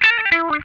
CRUNCH LICK1.wav